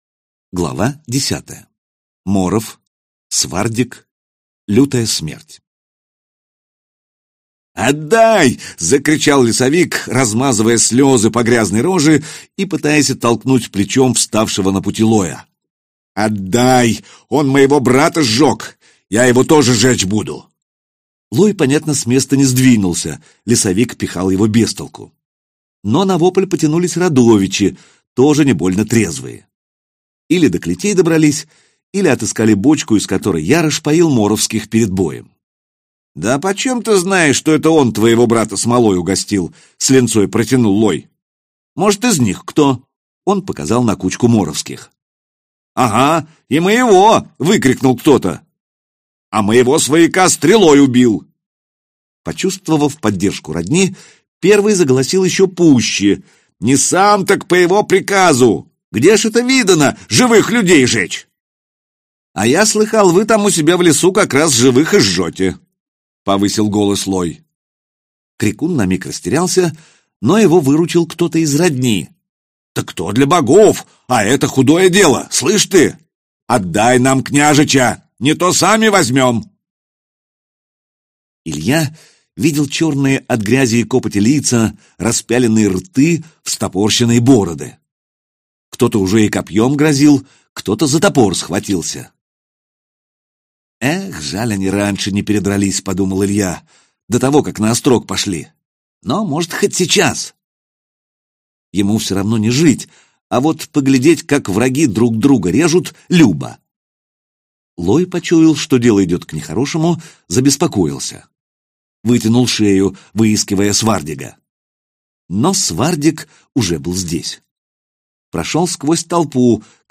Аудиокнига Богатырь - купить, скачать и слушать онлайн | КнигоПоиск